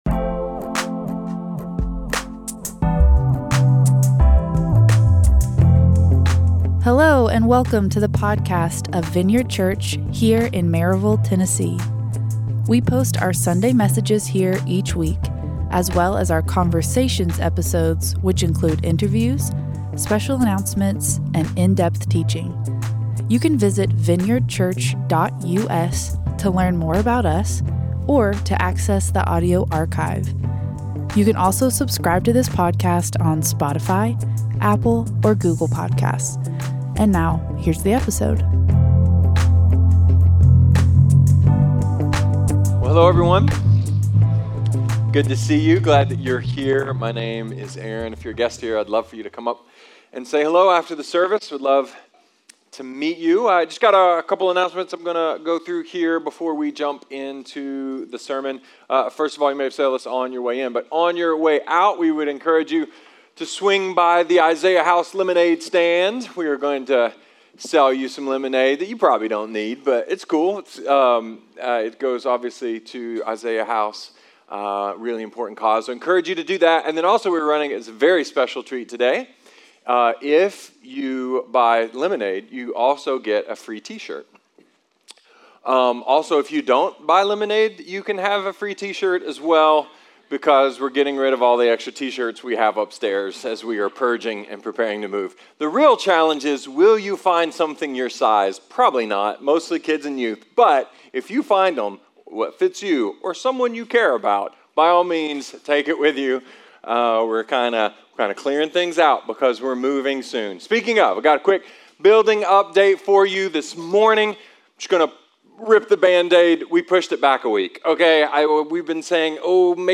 A message about HDTV, toga parties, adoption and freedom.